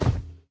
sounds / mob / irongolem / walk2.ogg
walk2.ogg